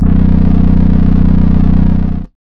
SGLBASS  3-L.wav